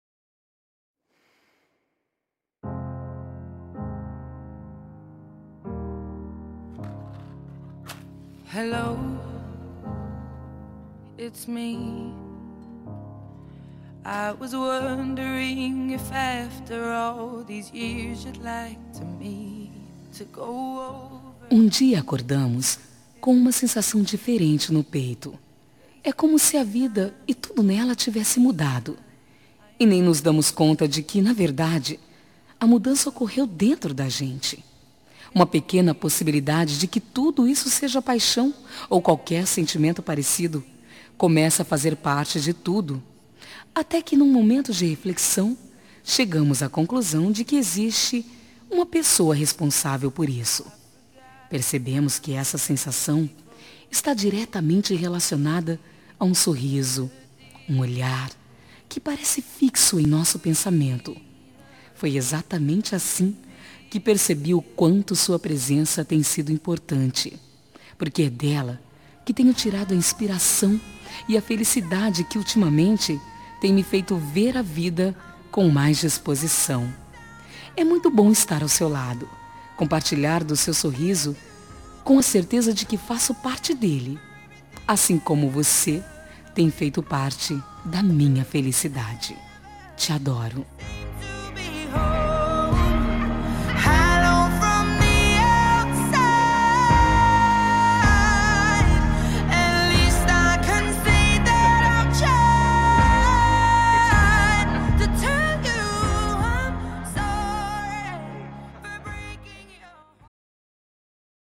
Telemensagem Início de Namoro – Voz Feminina – Cód: 745